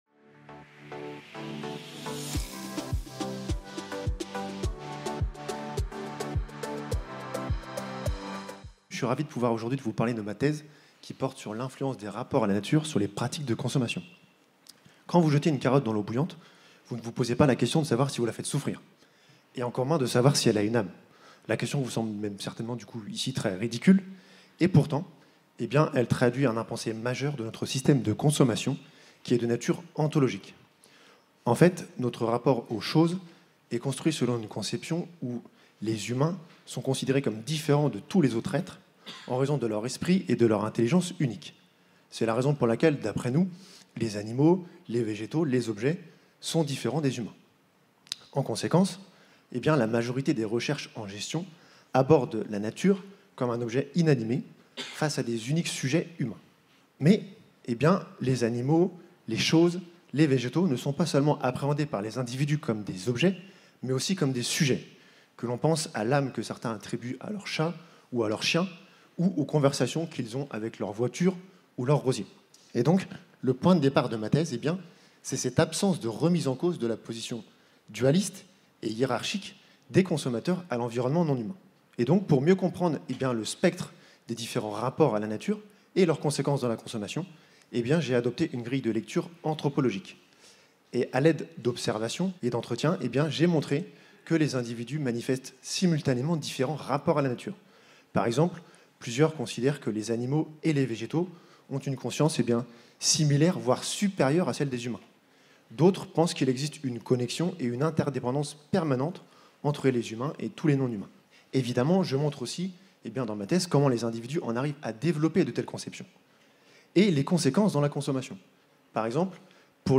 Pitch pour le Prix FNEGE de la Meilleure Thèse en Management 2024 (thèse en 180 secondes) – Prix de thèse AFM Cette recherche examine l’influence des rapports à la nature sur les pratiques de consommation.